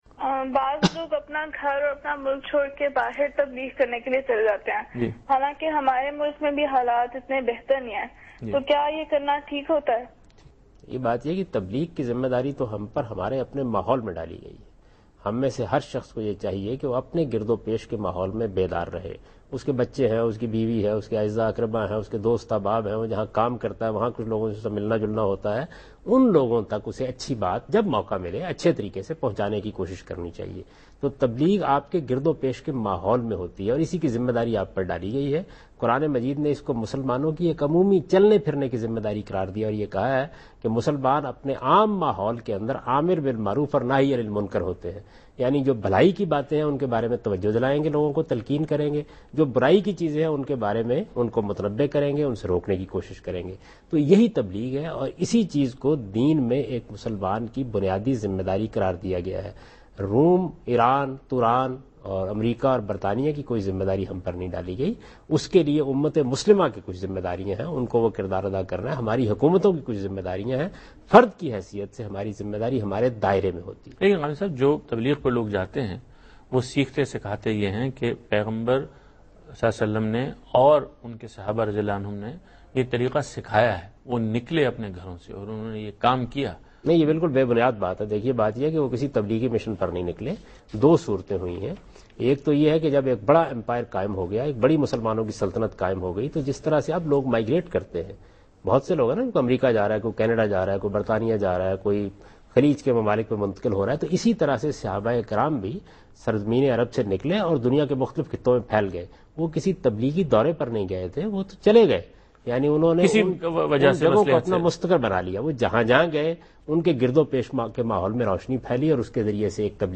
TV Programs
Javed Ahmad Ghamidi Answers a question about "Leaving Country for Preaching" in program Deen o Daanish on dunya News.
جاوید احمد غامدی دنیا نیوز کےپروگرام دین و دانش میں تبلیغ کے سلسلہ میں ملک چھوڑنے سے متعلق ایک سوال کا جواب دے رہے ہیں